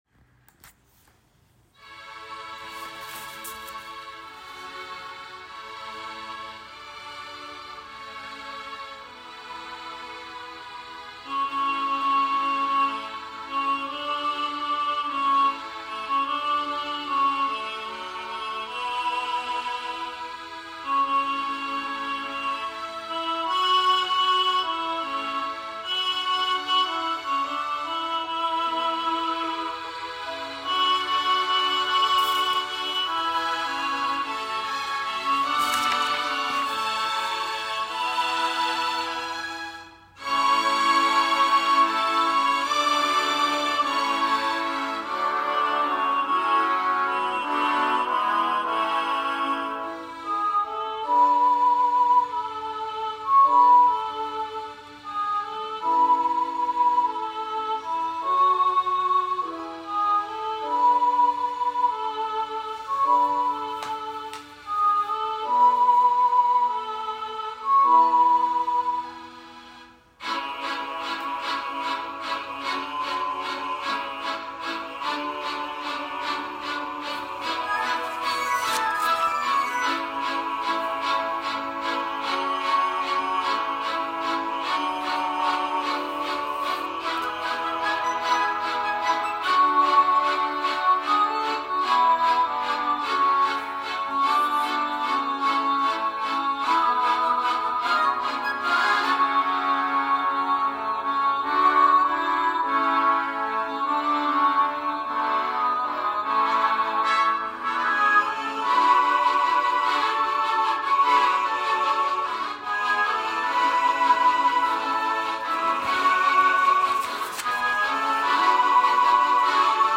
This is a piano reduction.
Voicing/Instrumentation: SATB quartet , Vocal Solo